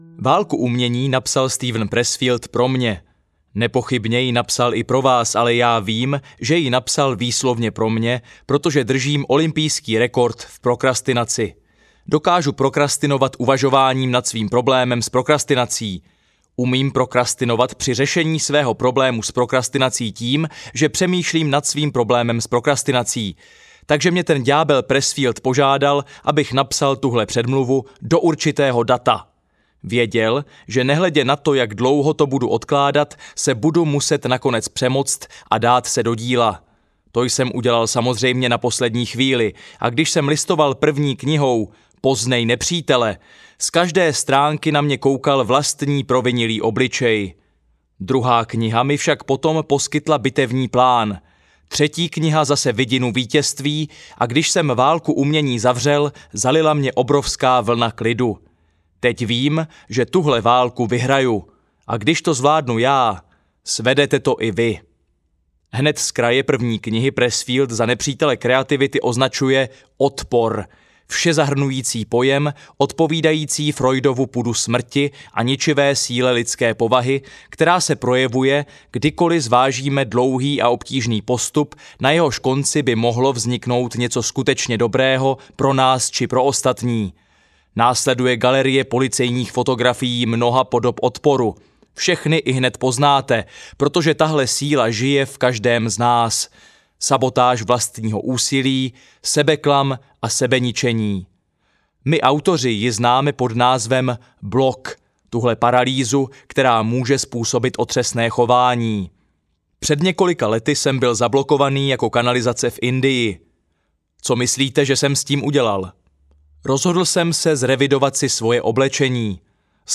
Válka umění audiokniha
Ukázka z knihy
valka-umeni-audiokniha